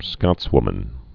(skŏtswmən)